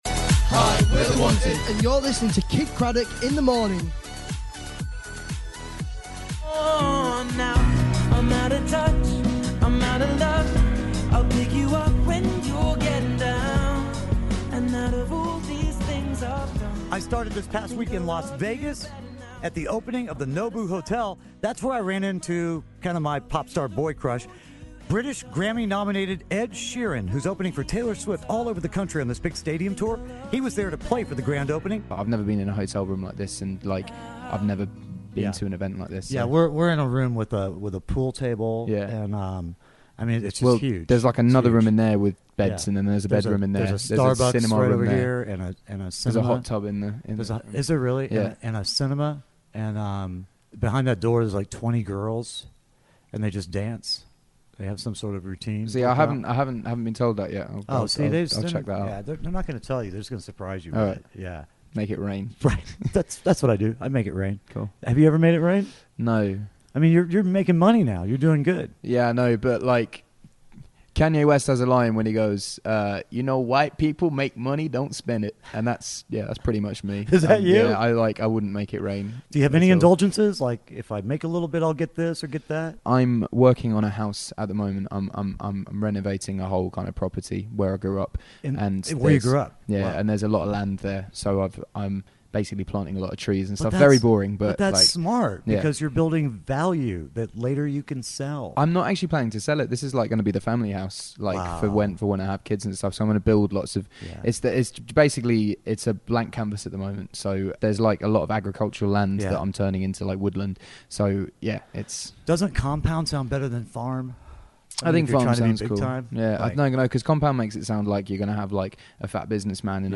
Ed Sheeran Interview
Kidd Kraddick interviews Ed Sheeran at the opening of the NOBU Hotel in Vegas!